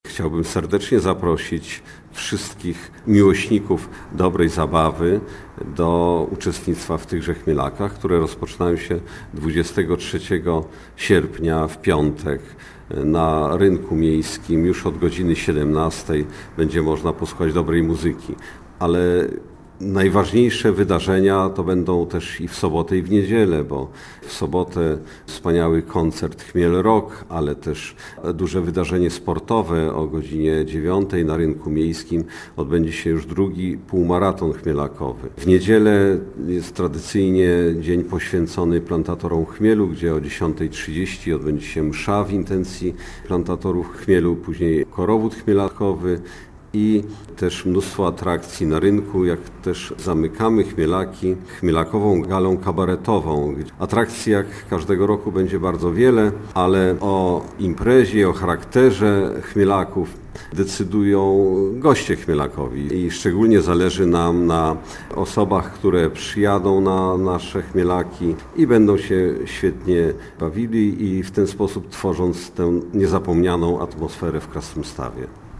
Warto w ten weekend odwiedzić Krasnystaw - zapewnia burmistrz Andrzej Jakubiec.